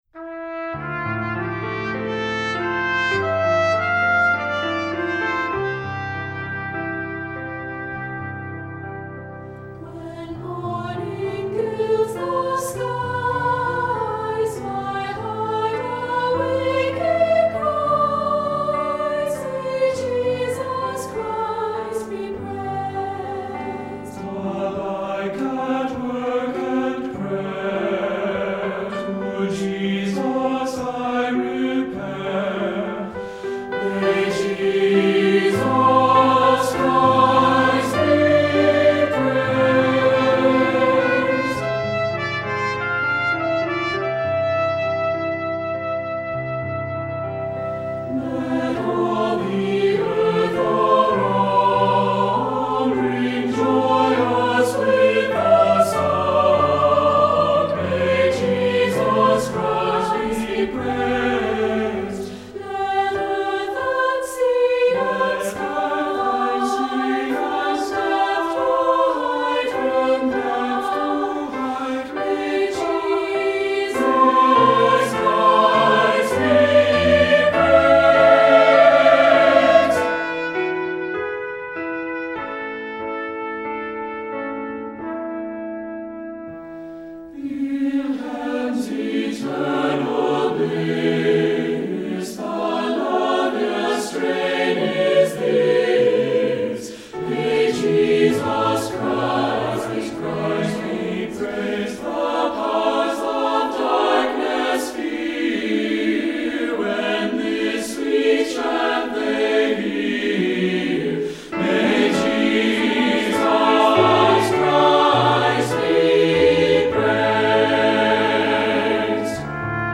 SAB Church Choir Music
Voicing: SATB